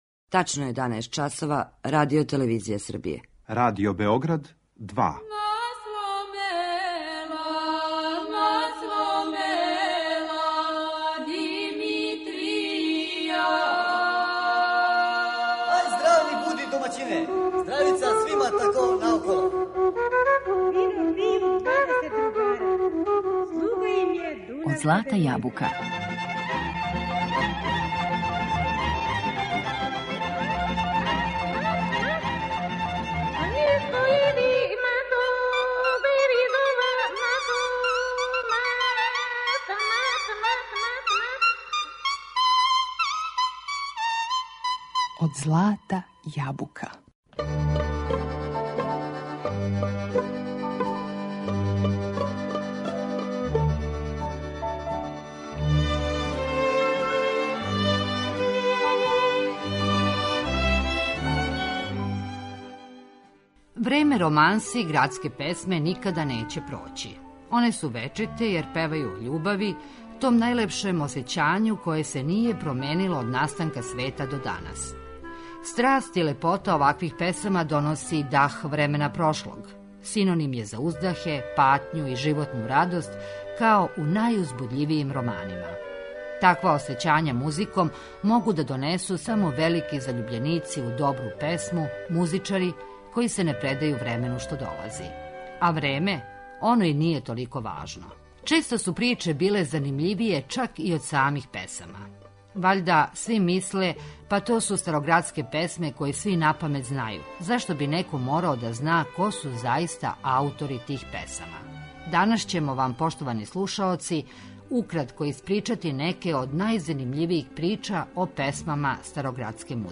Романсе и староградске песме